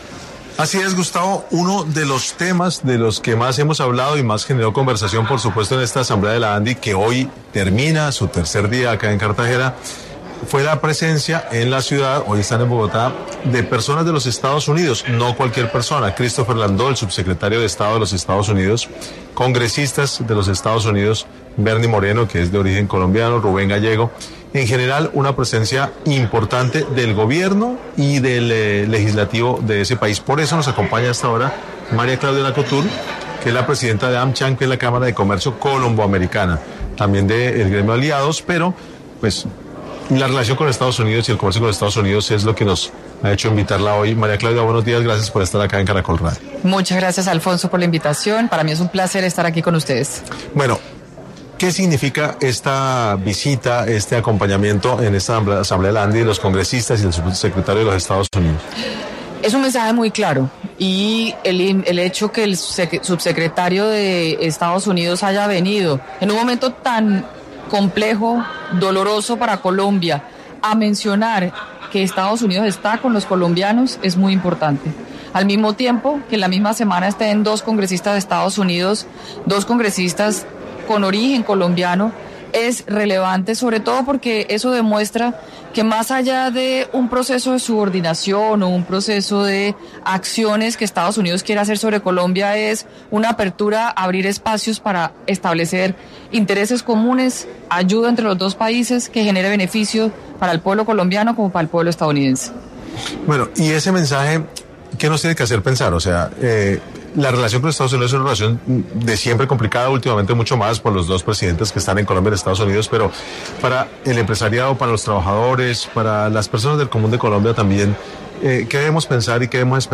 En Caracol Radio estuvo María Claudia Lacouture, presidenta de Amcham, quien dio detalles de las más recientes acciones del país norteamericano.
En medio del Congreso de la Andi, la exministra de Comercio y actual presidenta de Amcham, María Claudia Lacouture, conversó con Caracol Radio y explicó que la visita de representantes de EE.UU. demuestra la importancia de relaciones entre ambos países.